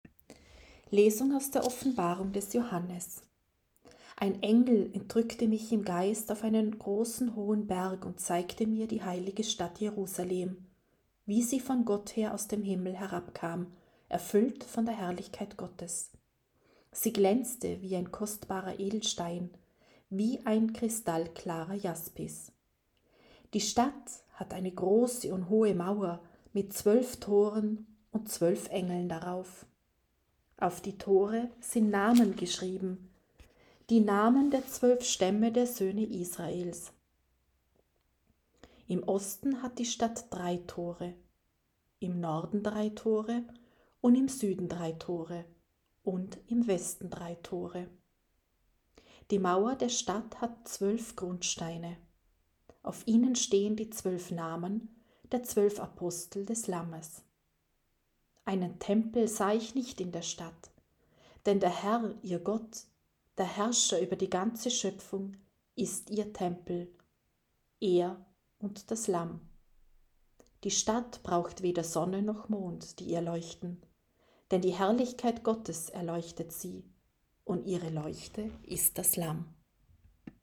C-6.-Sonntag-d-Osterzeit-2.-Lesung.mp3